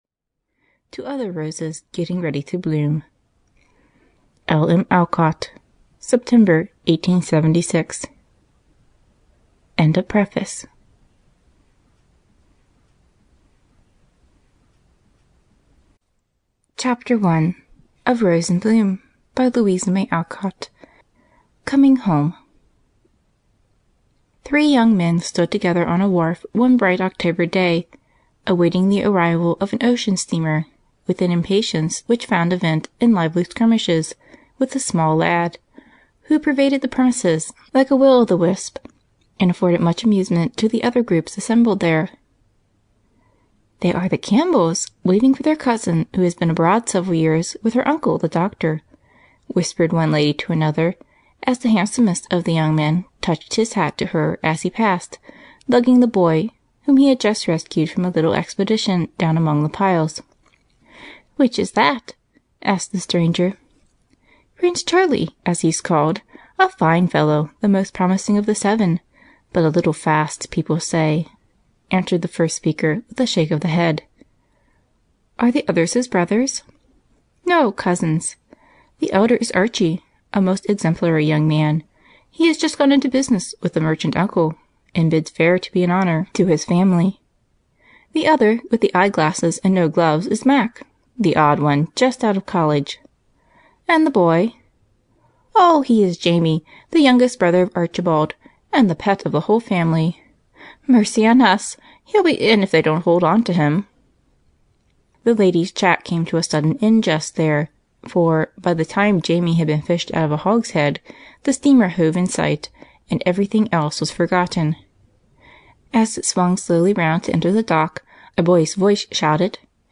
Rose in Bloom (EN) audiokniha
Ukázka z knihy